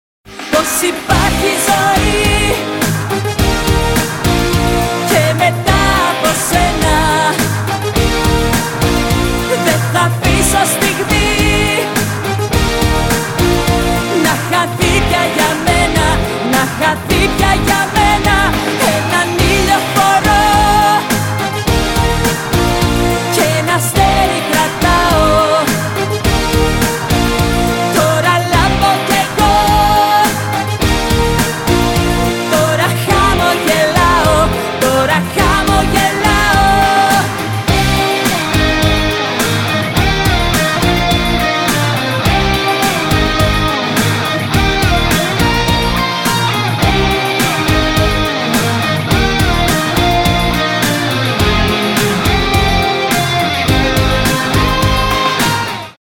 • Качество: 320, Stereo
ритмичные
красивый женский вокал
Греческие